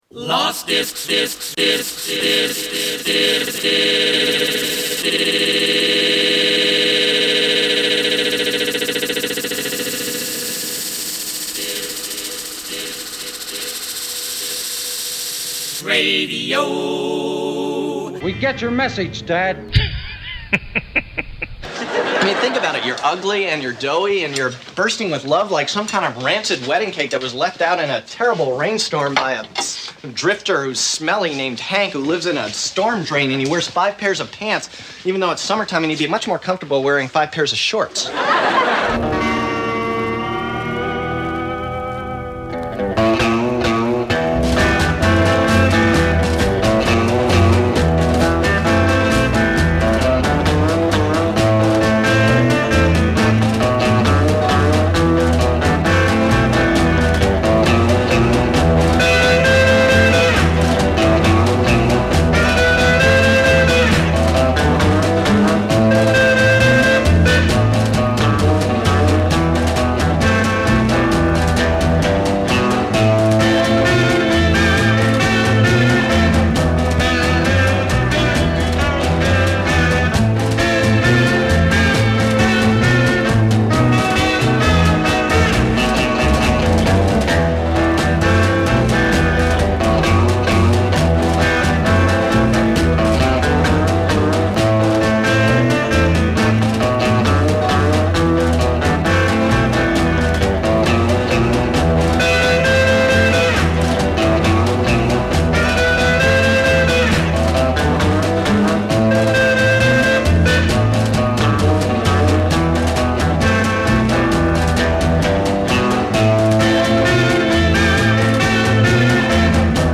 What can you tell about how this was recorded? As broadcast live via 5110kc 6/7/14